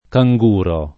[ ka jg2 ro ]